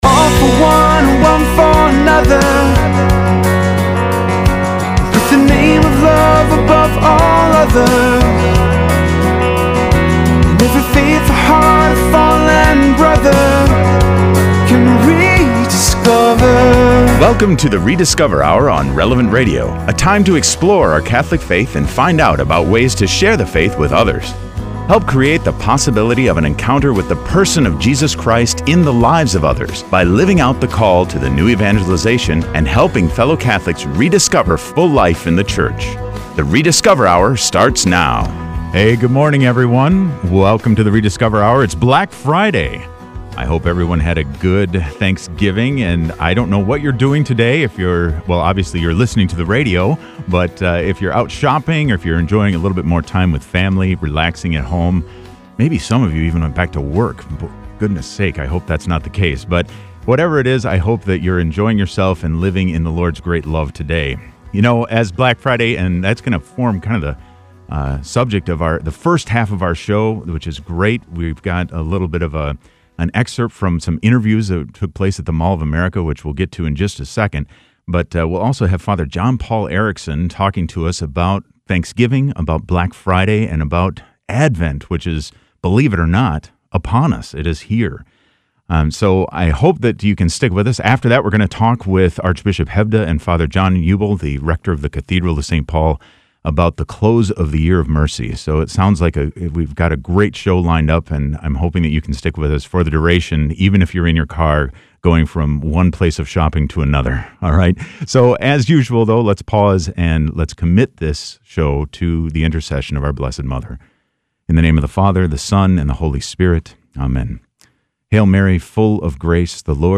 Archbishop Bernard Hebda joins us as well to speak on the conclusion of the Year of Mercy.